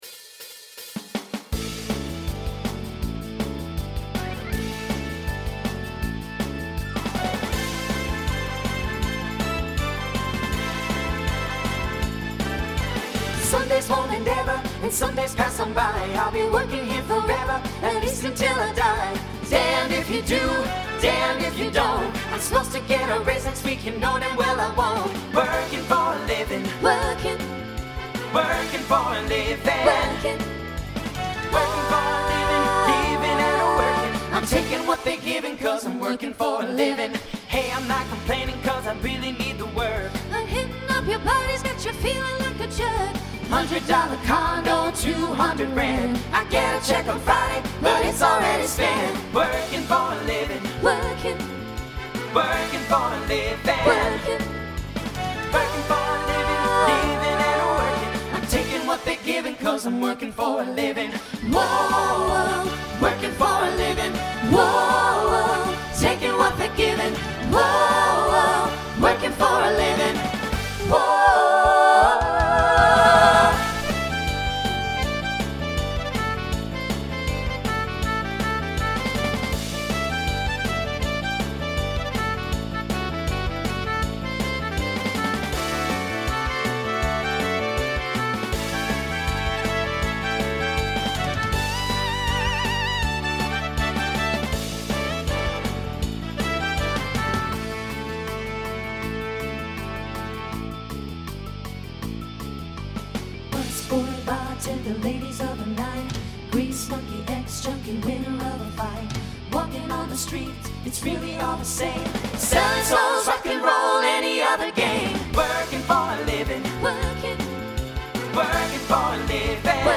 Voicing SATB Instrumental combo Genre Rock
Show Function Opener